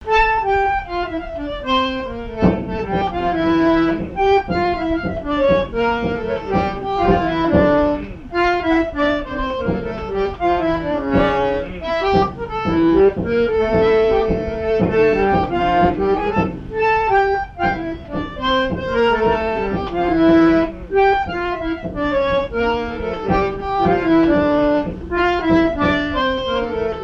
danse : tango
Vie de l'orchestre et son répertoire, danses des années 1950
Pièce musicale inédite